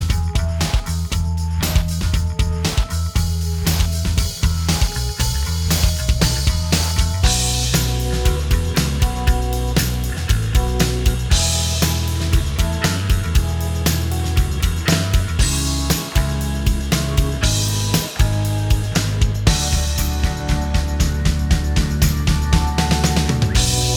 Minus Main Guitars Indie / Alternative 4:20 Buy £1.50